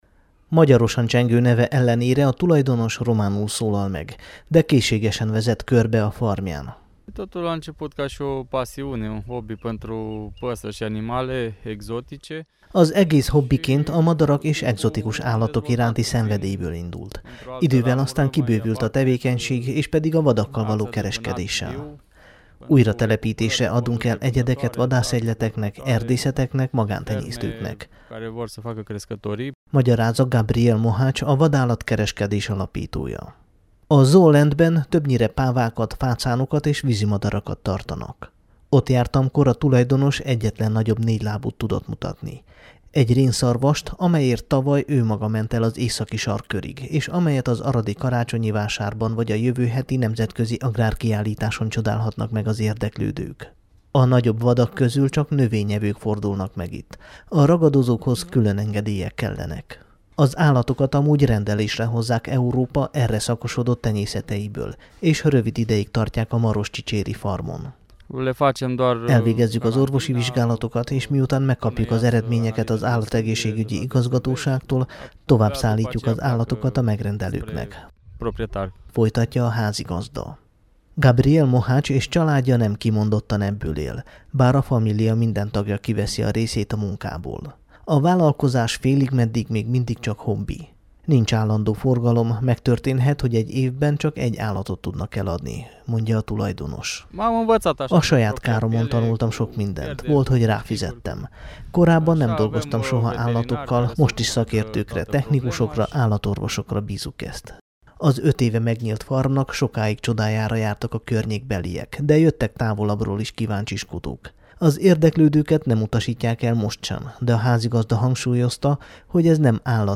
hogy riportot készítsen a Kossuth Rádió Hajnal-Táj című műsora számára.